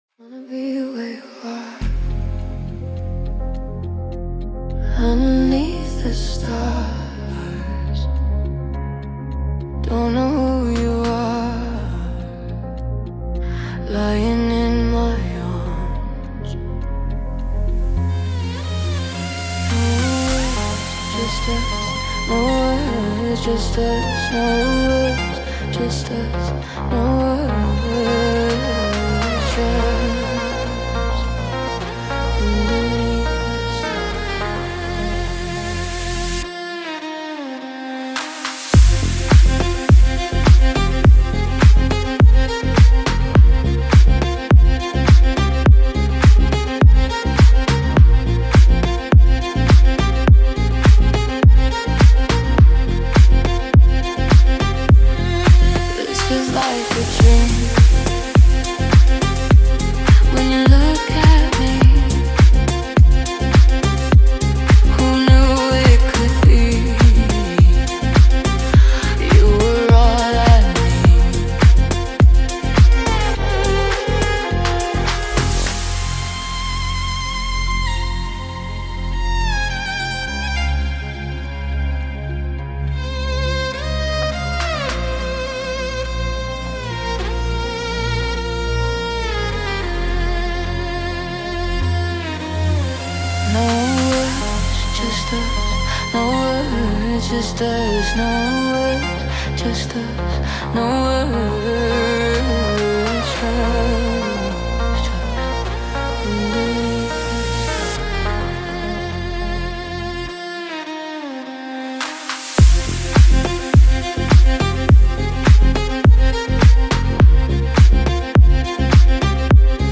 Talented vocalist